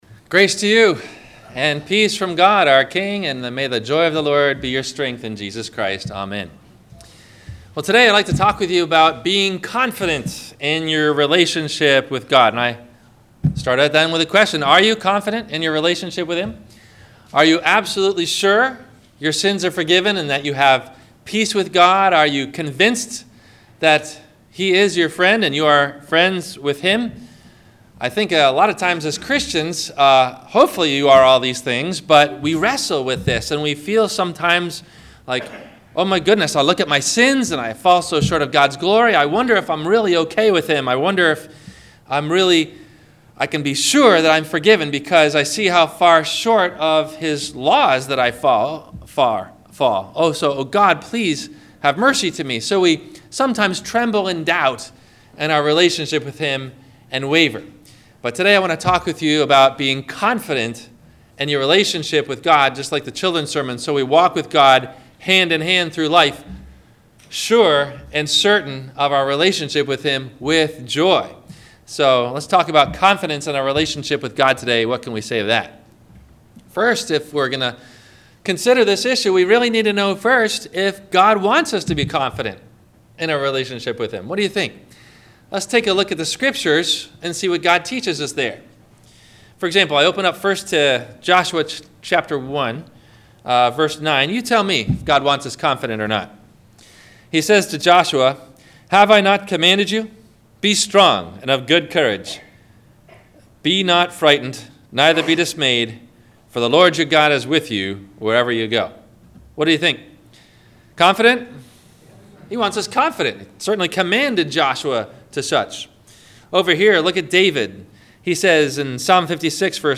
Are You Confident of Your Relationship with God? - Sermon - March 26 2017 - Christ Lutheran Cape Canaveral